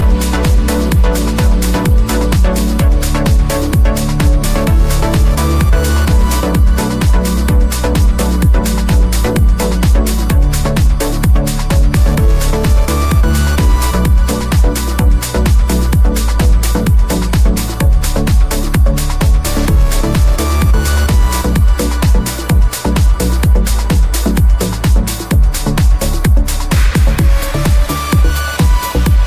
King One of the Best Prog.Trance track ID wellknown
melodic progressive house & trance track